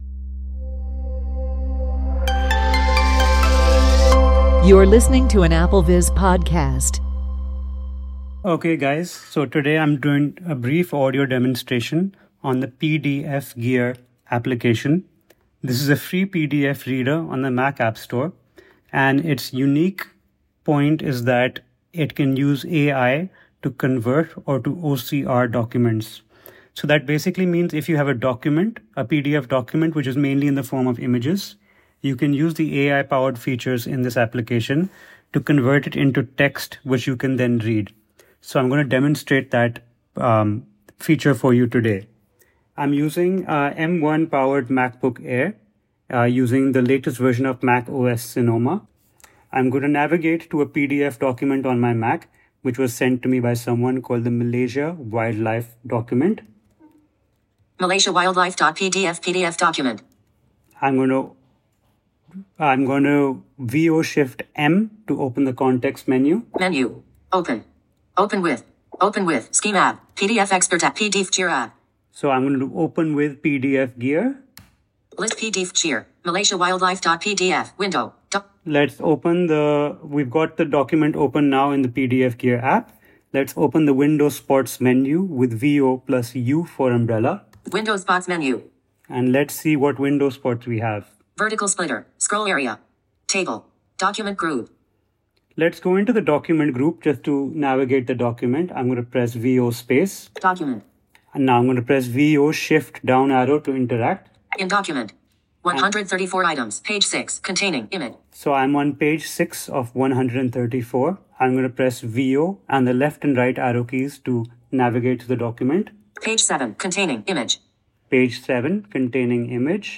Walk-through